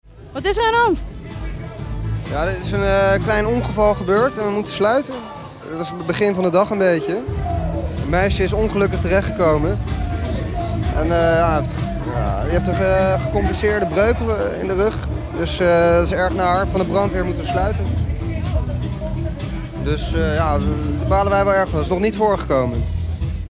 was ter plaatse en sprak kort met iemand van de bewaking.
KEI-JOURNAAL_ongeval_openair.mp3